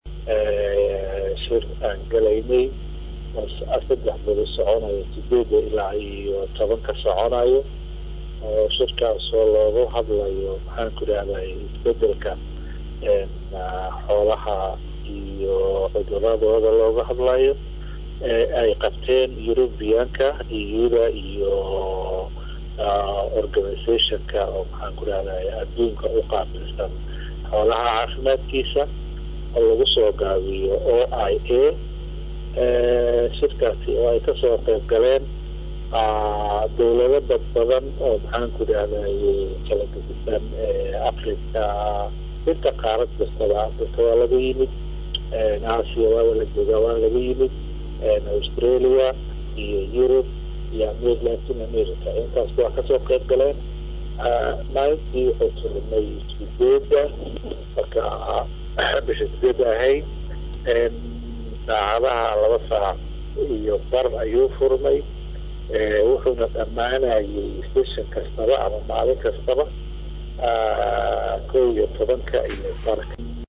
Xildhibaan Siciid Maxamed Cali oo ah gudoomiyaha gudiga xoolaaha xanaanada iyo baarlamaanka ayaa Idaacadda Risaala muqdisho waxa uu kaga warbixiyay shirkaas iyo waxyaabaha ugu muhiimsan ee looga hadlay.